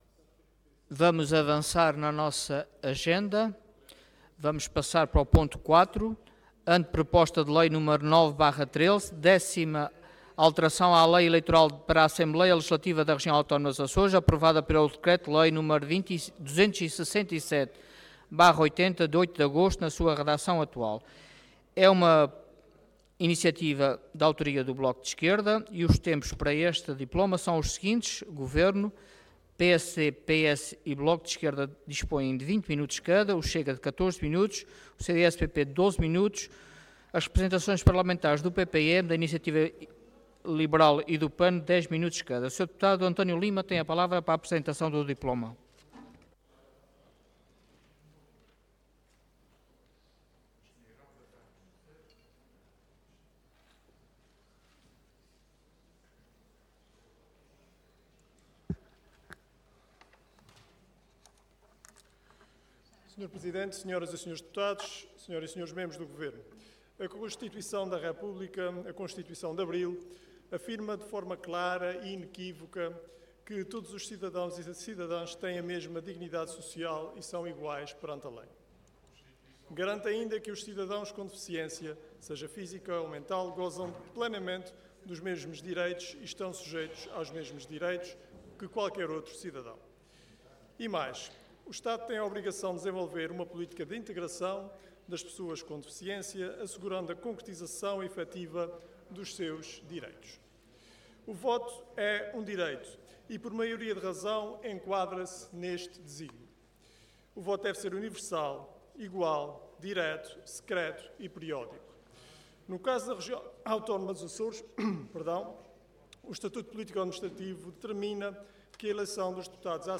Detalhe de vídeo 2 de junho de 2025 Download áudio Download vídeo Processo XIII Legislatura Décima alteração à Lei Eleitoral para a Assembleia Legislativa da Região Autónoma dos Açores, aprovada pelo Decreto-Lei n.º 267/80, de 8 de agosto, na sua redação atual Intervenção Anteproposta de Lei Orador António Lima Cargo Deputado Entidade BE